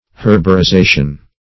herborization - definition of herborization - synonyms, pronunciation, spelling from Free Dictionary
Search Result for " herborization" : The Collaborative International Dictionary of English v.0.48: Herborization \Her`bo*ri*za"tion\, n. [F. herborisation.] 1.